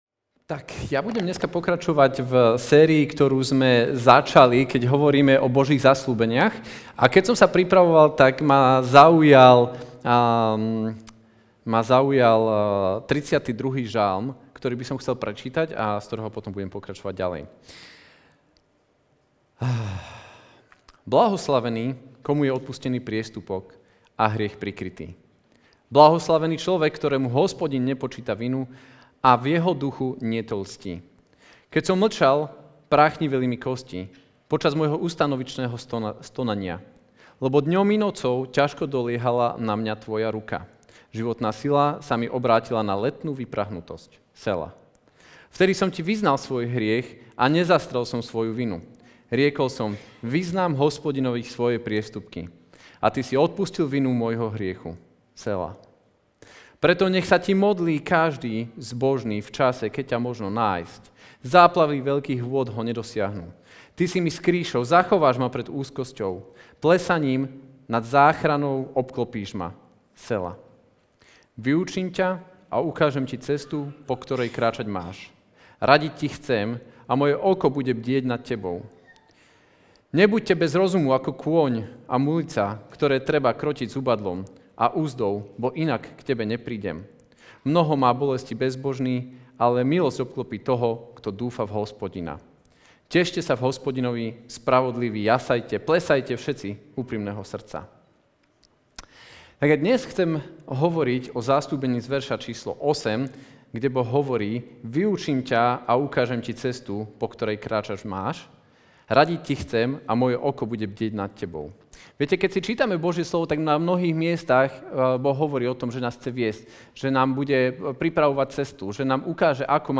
Budem ťa viesť Kázeň týždňa Zo série kázní